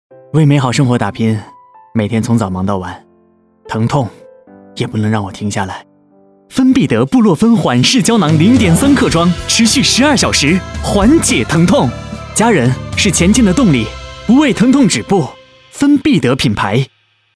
• 21专业男声8
活力广告